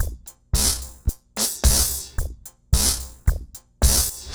RemixedDrums_110BPM_01.wav